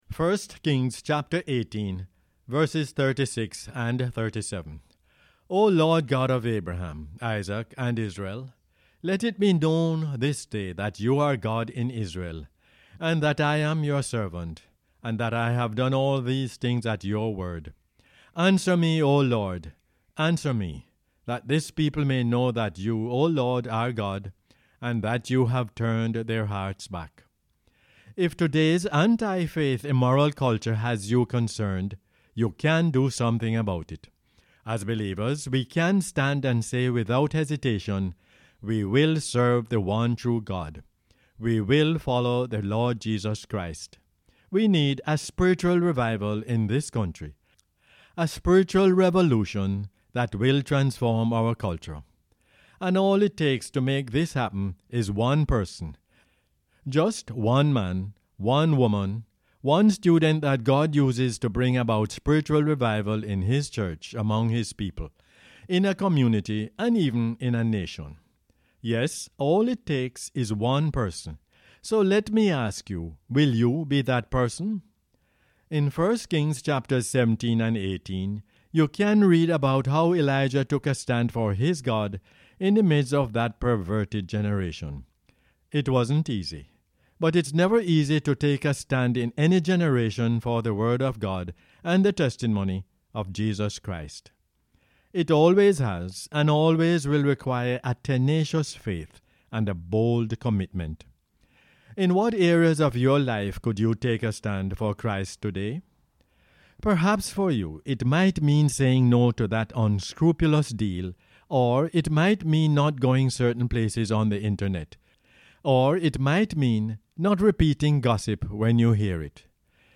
1 Kings 18:36-37 is the "Word For Jamaica" as aired on the radio on 5 November 2021.